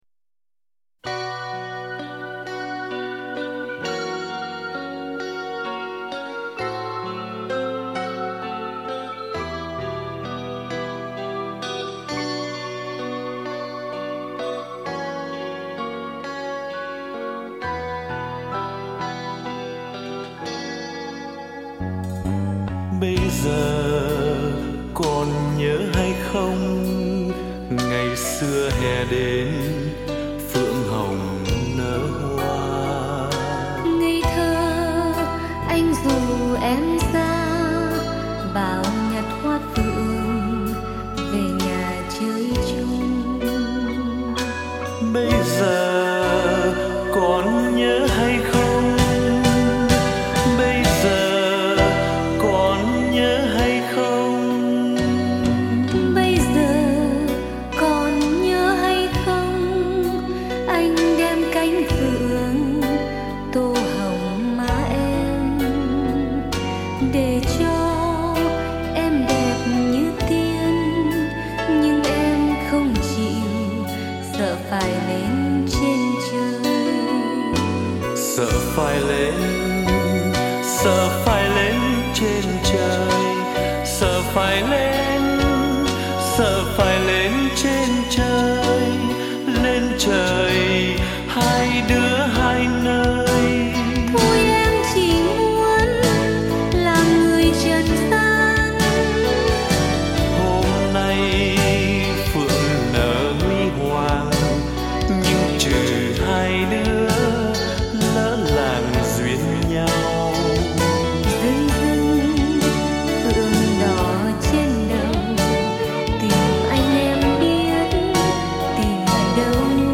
giọng ca rất hiền, rất học trò.